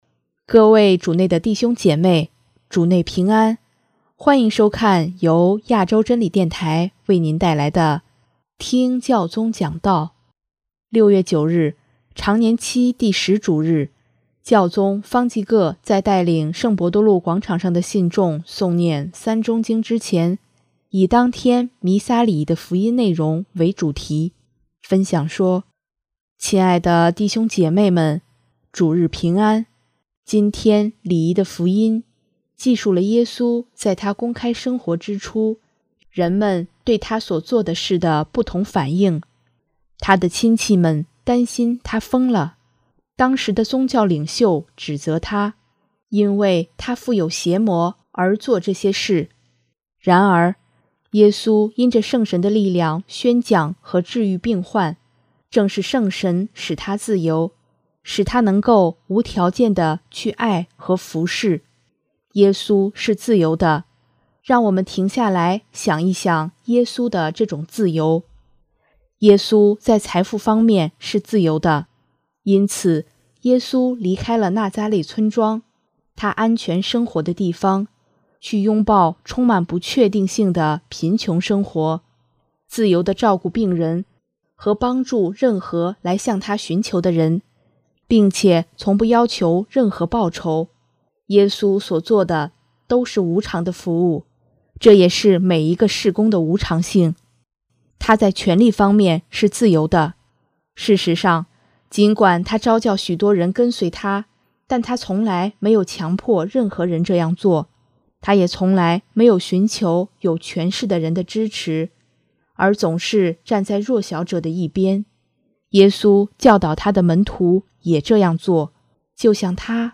【听教宗讲道】|耶稣是一个自由人
6月9日，常年期第十主日，教宗方济各在带领圣伯多禄广场上的信众诵念《三钟经》之前，以当天弥撒礼仪的福音内容为主题，分享说：